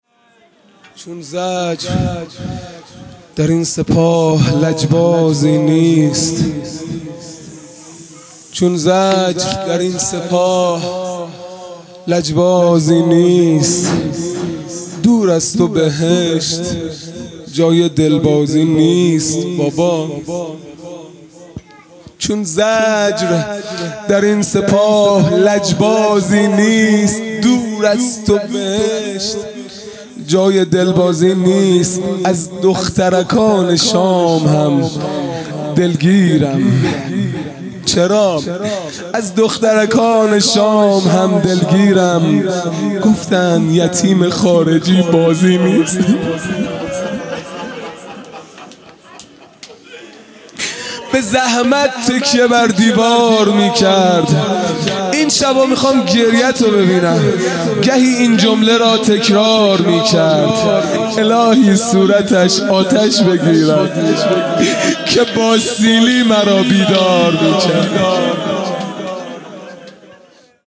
خیمه گاه - هیئت بچه های فاطمه (س) - مرثیه | چون زجر در این سپاه لجبازی نیست